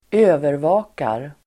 Uttal: [²'ö:verva:kar]